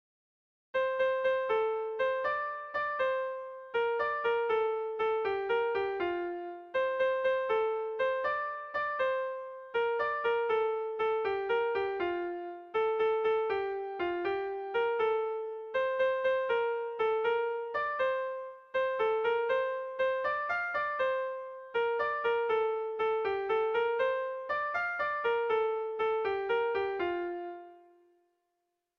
Erlijiozkoa
Zortziko ertaina (hg) / Lau puntuko ertaina (ip)
AABD